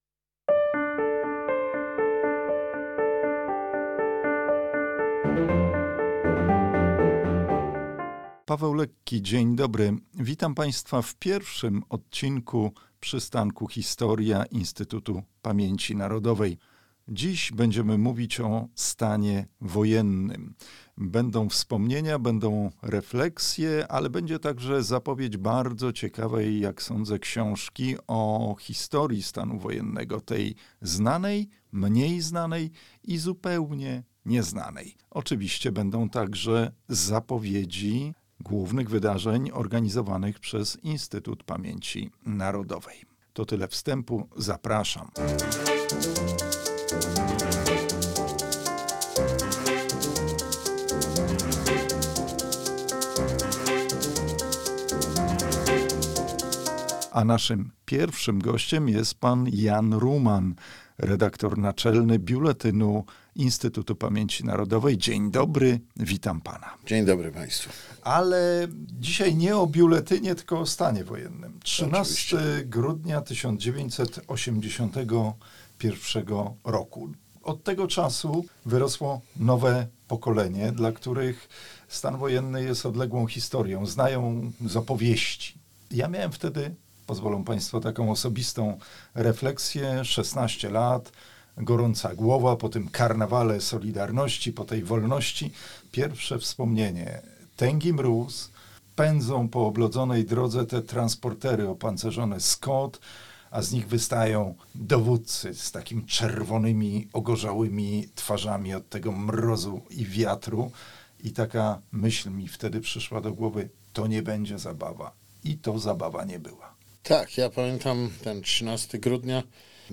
Osią audycji są rozmowy